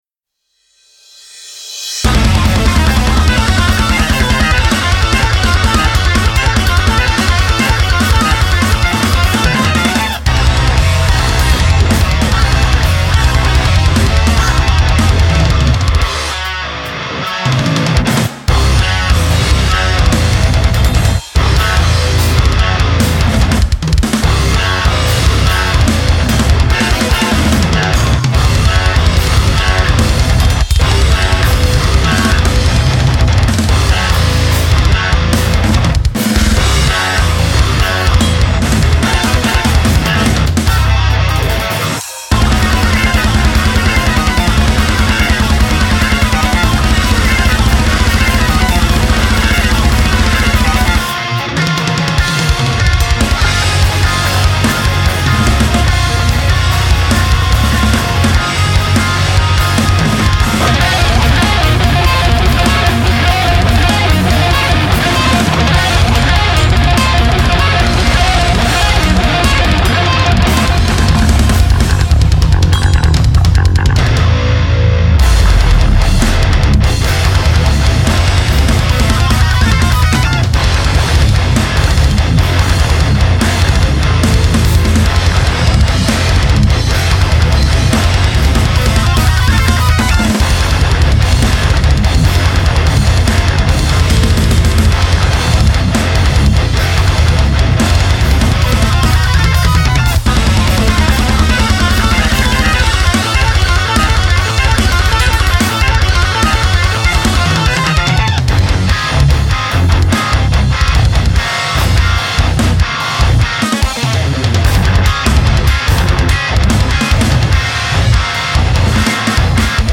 instruMETAL